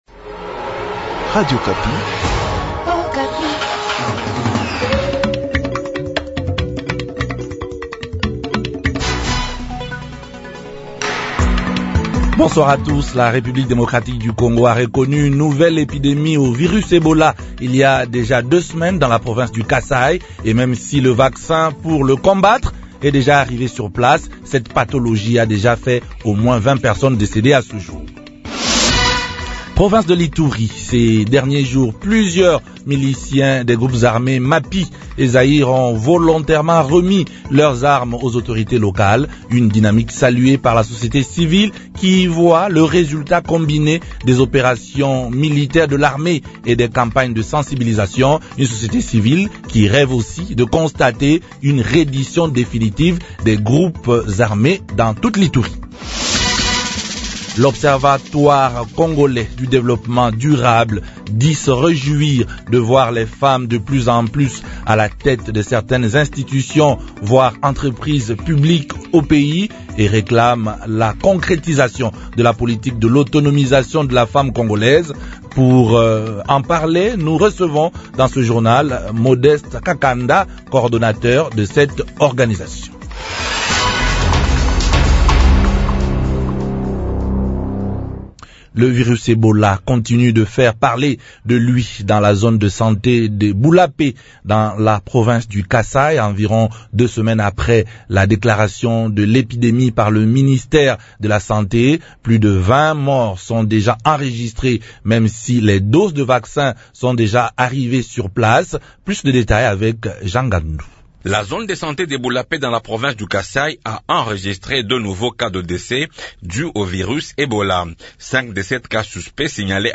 journal frncais